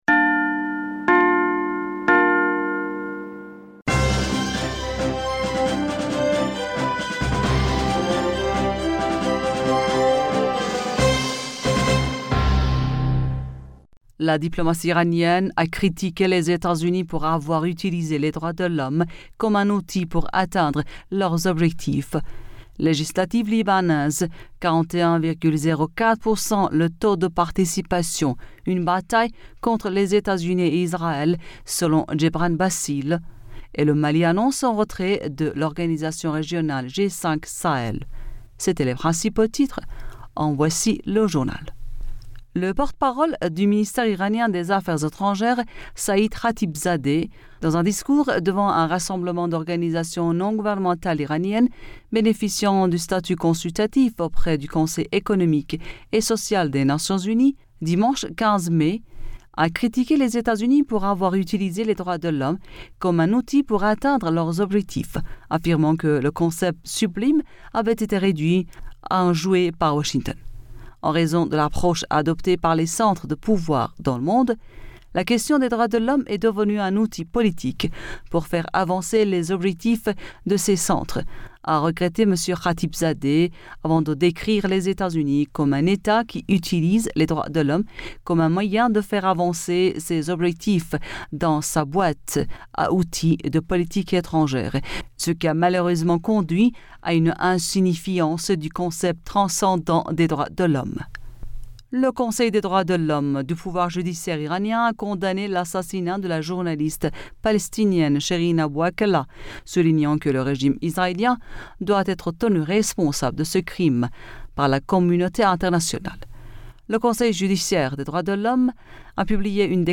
Bulletin d'information Du 16 Mai 2022